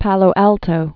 (pălō ăltō)